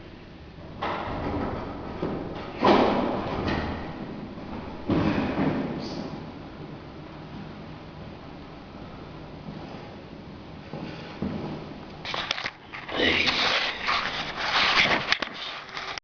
EVP2 (Electronic Voice Phenomena) I have heard in eight years of paranormal investigating.
bath house evp.WAV